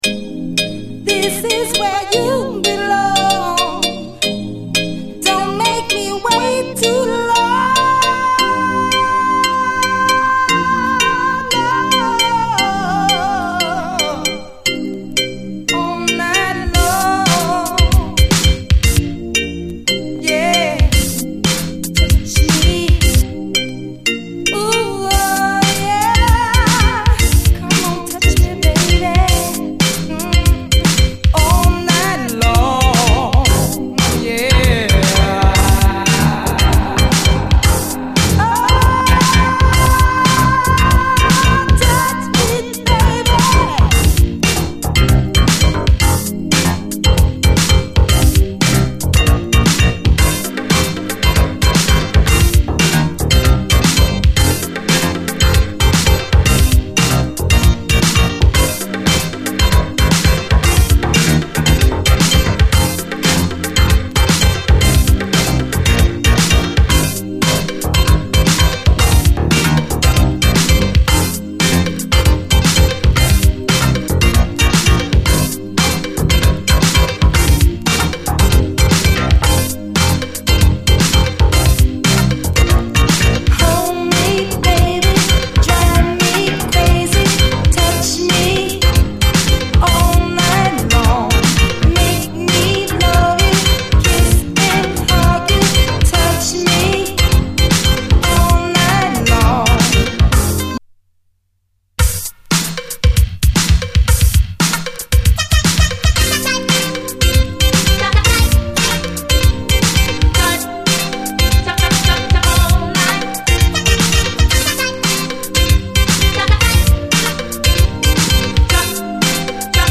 ピアノが綺麗なスローモー・ディスコ
ピアノ・ハウスそのものなガラージ・クラシック